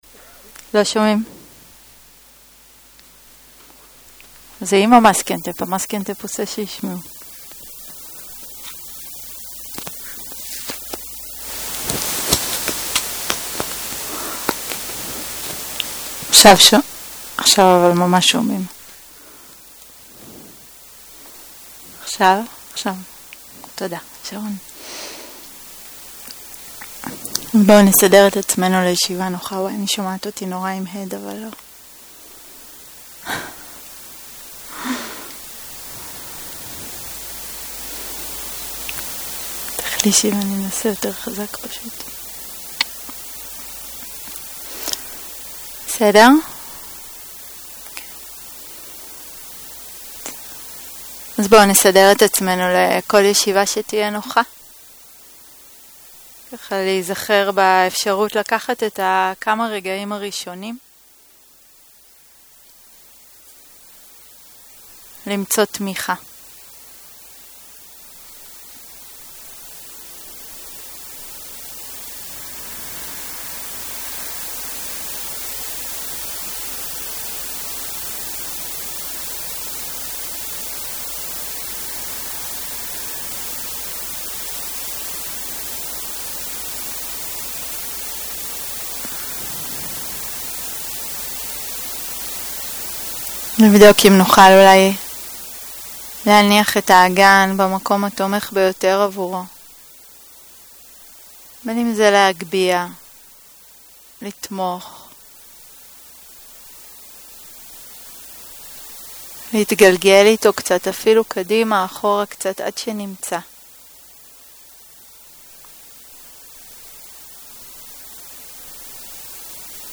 מדיטציה מונחית - לאפשר ולהרפות
סוג ההקלטה: מדיטציה מונחית